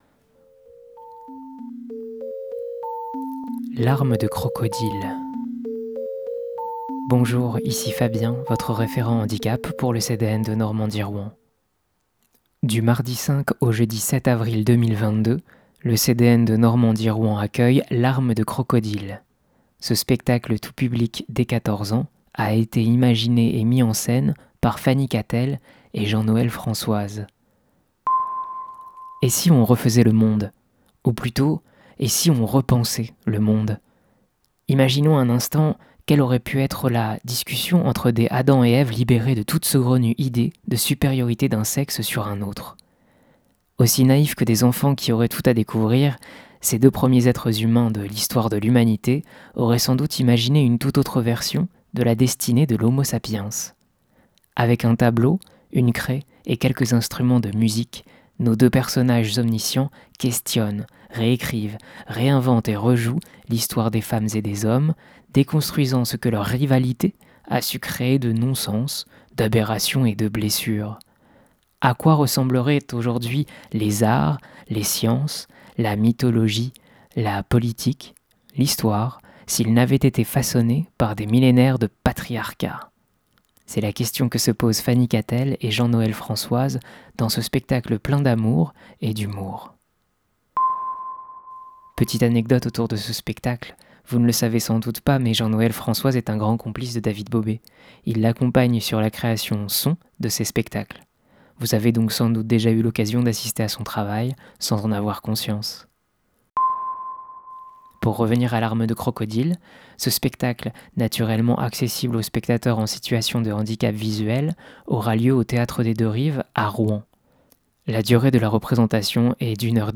Écouter la présentation du spectacle